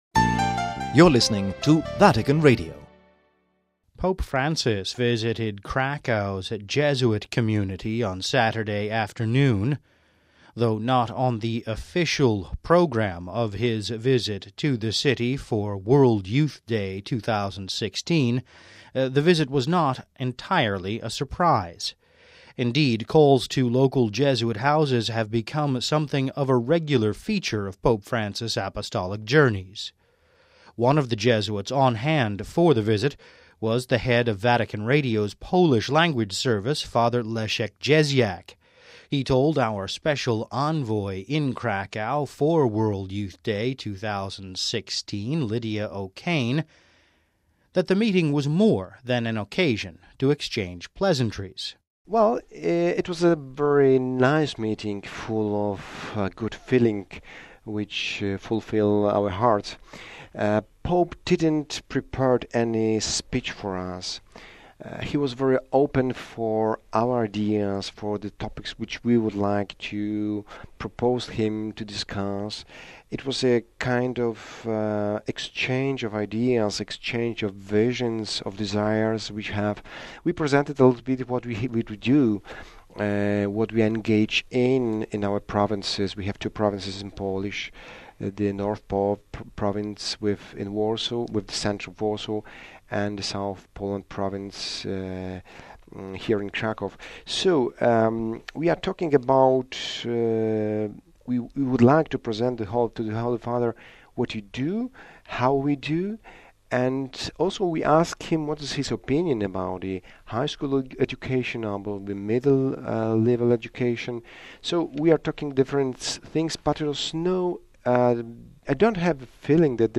extended conversation